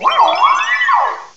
cry_not_mesprit.aif